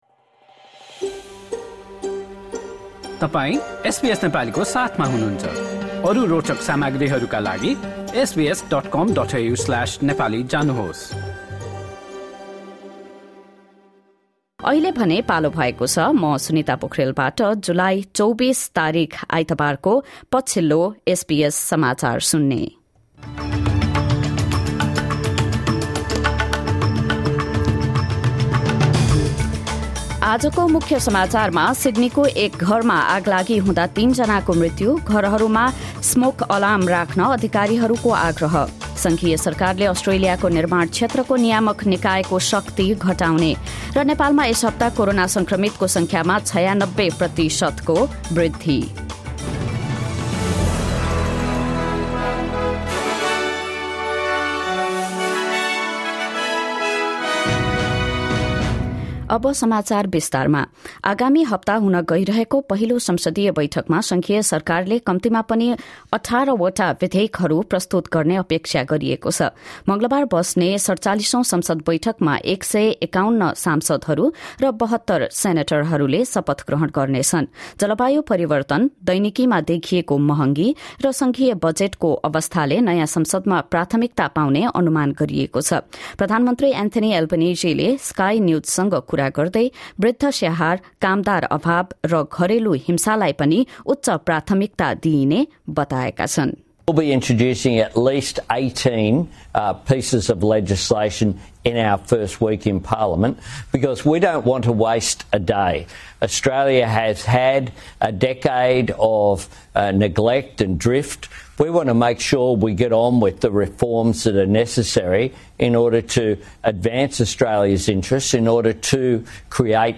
Listen to the latest news headlines from Australia in Nepali. In this bulletin: The Federal Government moves to reduce the powers of the Australian Building and Construction Commission. Three people die in a Sydney housefire, with authorities pleading the importance of working smoke alarms.